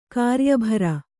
♪ kāryabhara